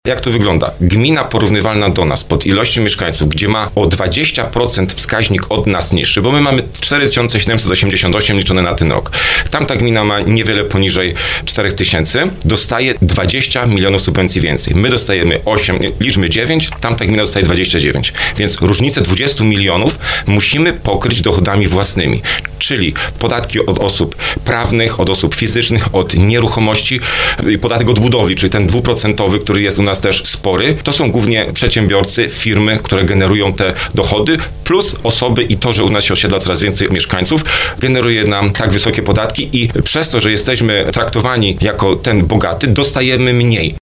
Wójt Skrzyszowa, Marcin Kiwior, w programie 'Słowo za Słowo” zaznaczył, że sam zamysł ustawy był dobry, bo miał wspierać słabsze finansowo samorządy, jednak zabrakło elementu równowagi, który pozwoliłby docenić również gminy aktywne i generujące wysokie wpływy podatkowe.